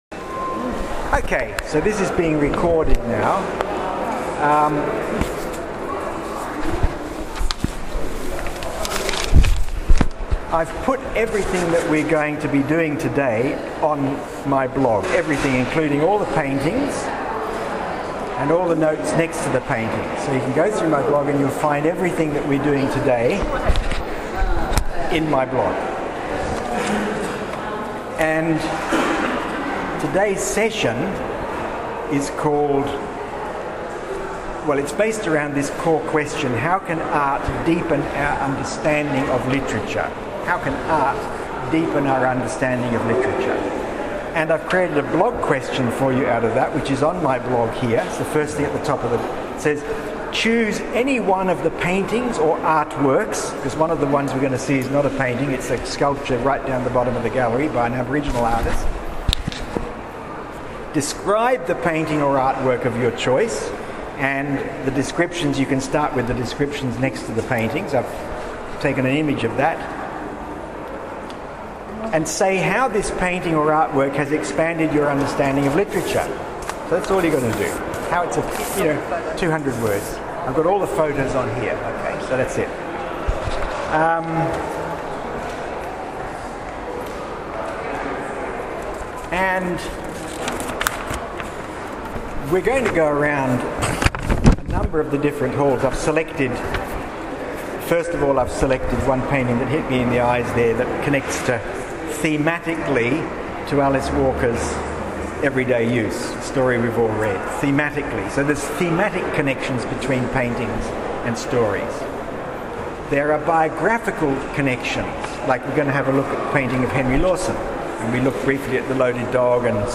Clemente Visit to the NSW Art Gallery 2017 ENGL104